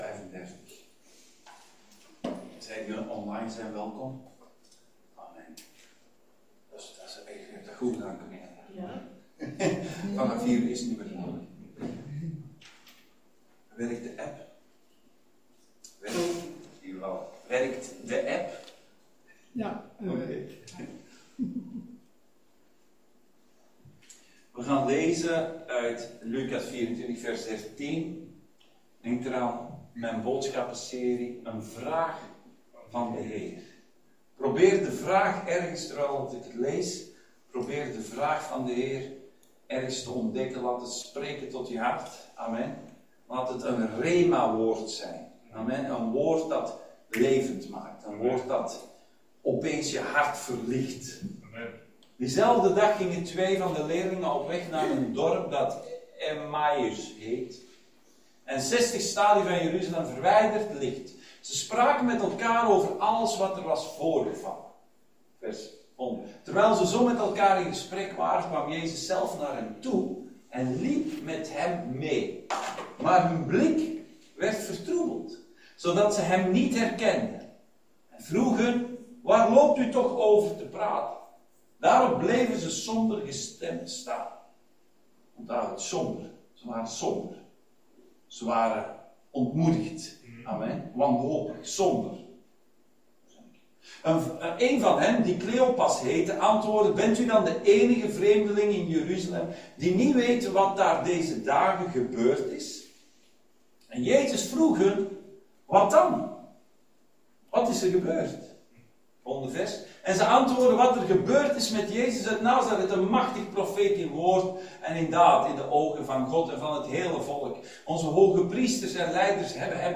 Een vraag van de Heer Dienstsoort: Zondag Dienst « Zwaard van Goliath Een vraag van de Heer